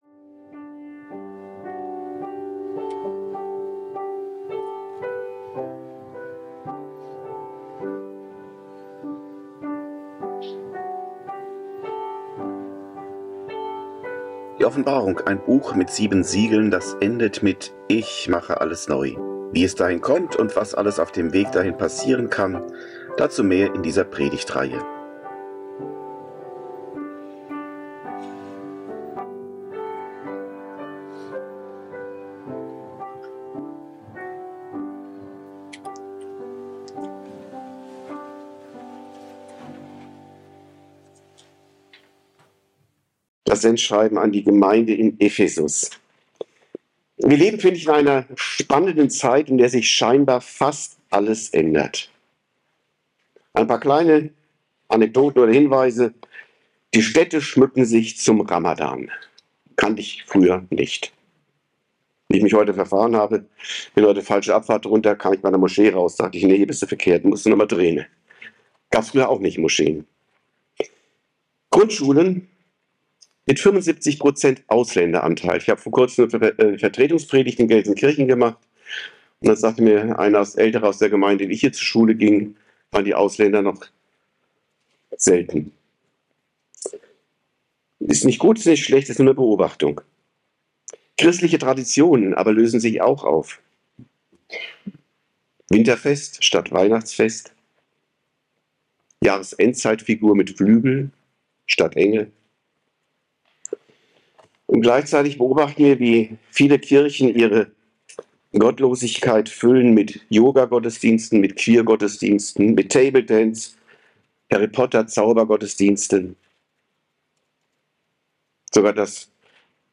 02 Jesus schreibt an seine Gemeinde in Ephesus ~ Predigten u. Andachten (Live und Studioaufnahmen ERF) Podcast